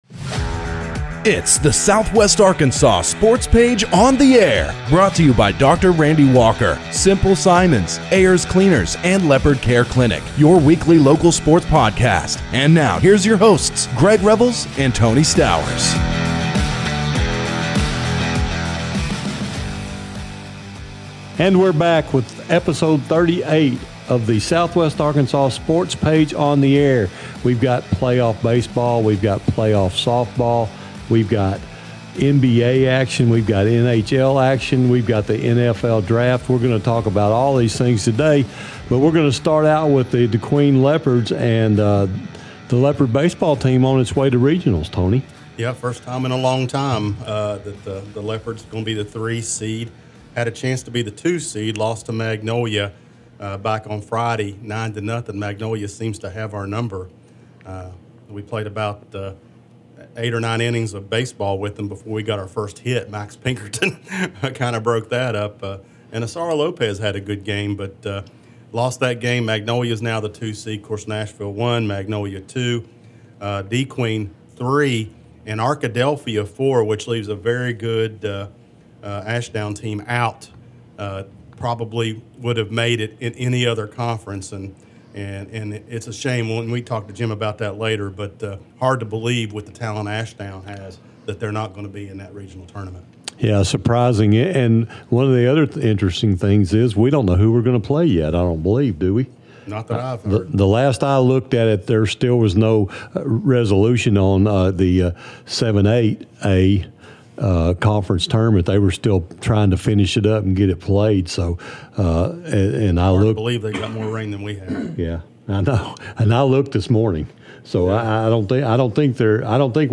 back in the studio to talk about the Dierks Outlaws.-The Boys discuss the NBA, the NHL, and the NFL Draft.